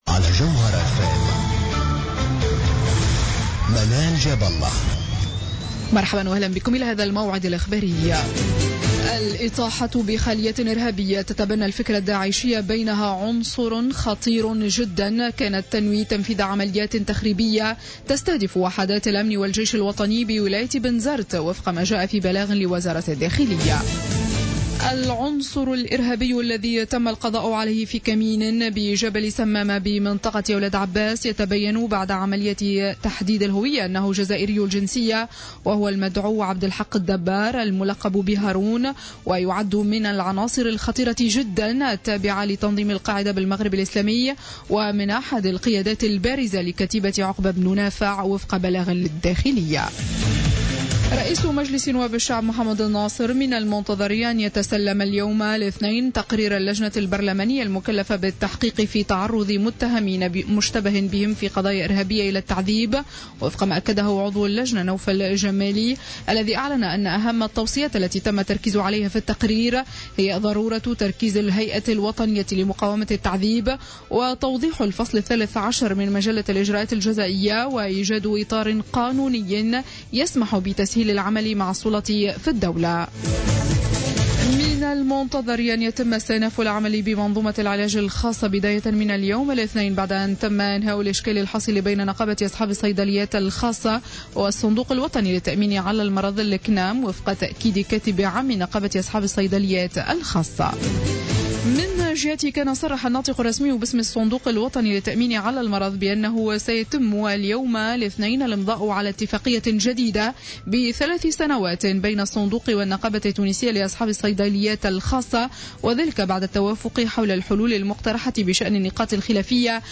نشرة أخبار منتصف الليل ليوم الإثنين 17 أوت 2015